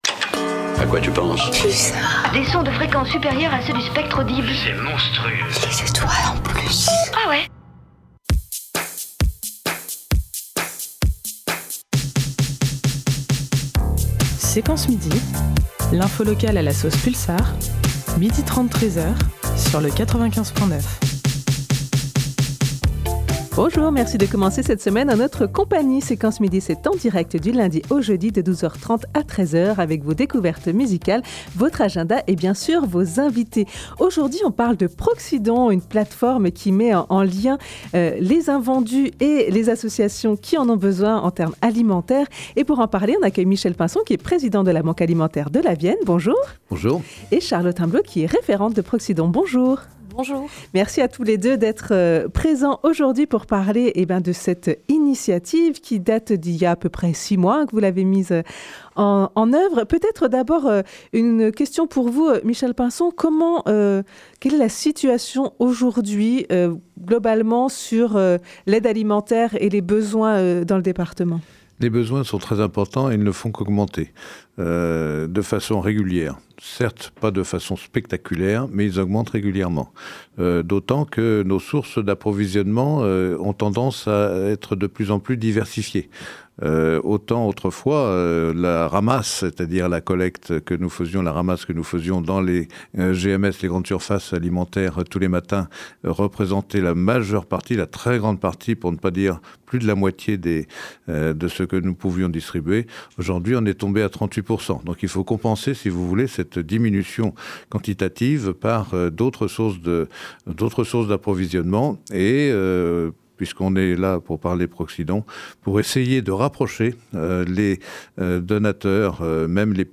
On prend les ondes à la pause déj’ pour vous donner le meilleur de l’actualité de Poitiers et de ses environs, avec nos invité-e-s, le tout à la sauce Pulsar. Ainsi que des chroniques, des reportages, des acteurs associatifs, etc…